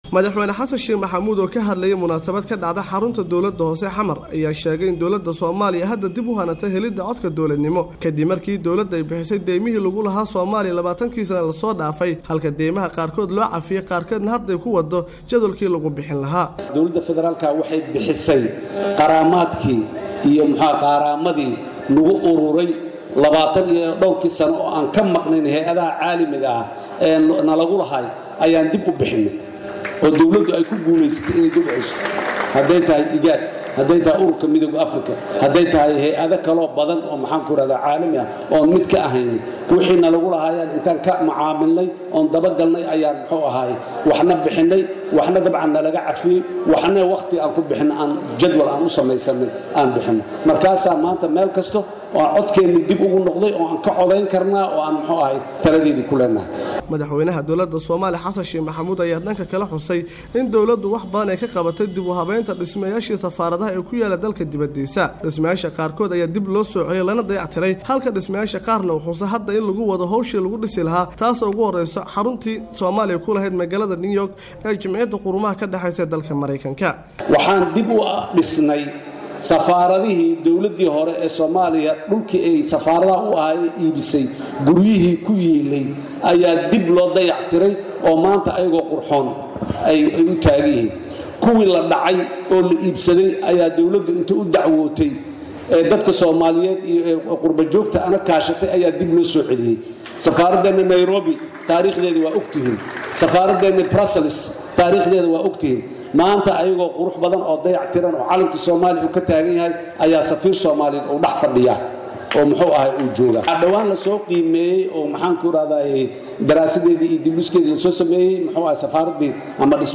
Khudbadda Madaxweyne Xasan